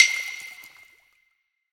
soft-hitfinish.ogg